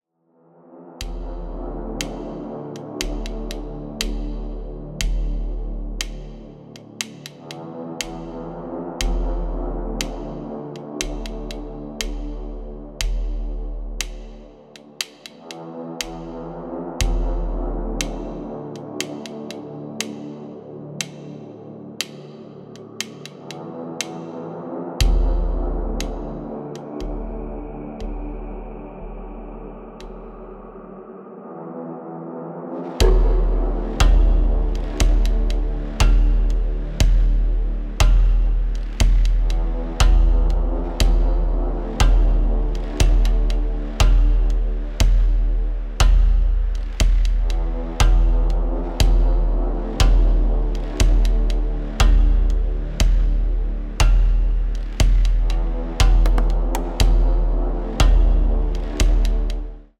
TOP >Vinyl >Grime/Dub-Step/HipHop/Juke
(Instrumental)